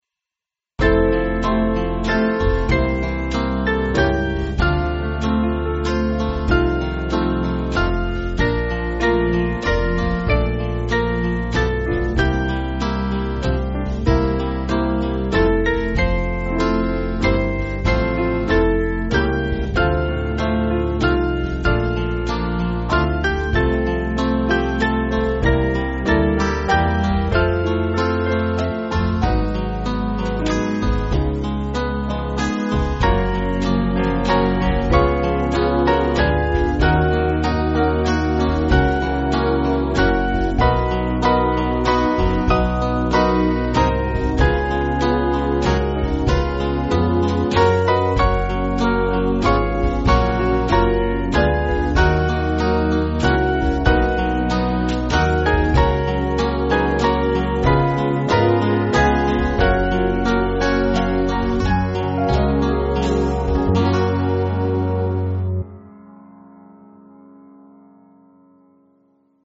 Small Band
(CM)   2/F-Gb